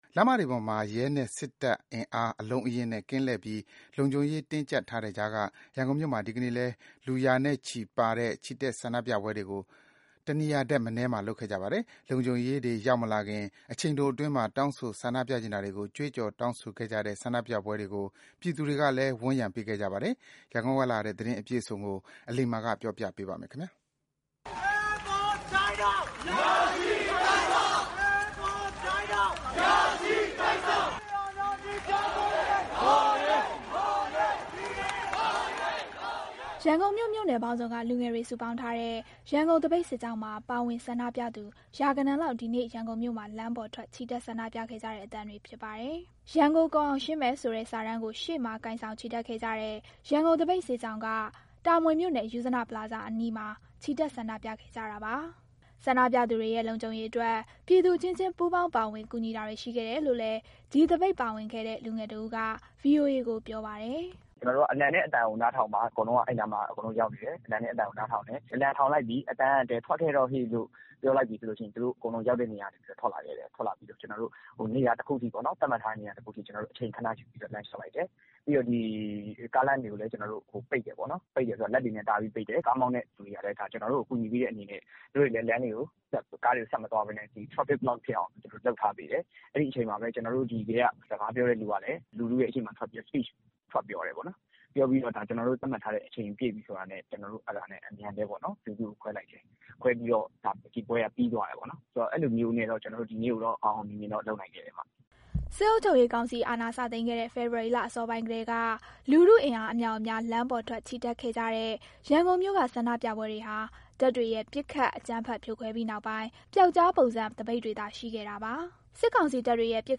ရန်ကုန်မြို့ မြို့နယ်ပေါင်းစုံက လူငယ်တွေစုပေါင်းထားတဲ့ ရန်ကုန်သပိတ်စစ်ကြောင်းမှာ ပါဝင်ဆန္ဒပြသူ ရာဂဏန်းလောက်ဒီနေ့ ရန်ကုန်မြို့မှာ လမ်းပေါ်ထွက် ချီတက်ဆန္ဒပြခဲ့ကြတဲ့အသံတွေဖြစ်ပါတယ်။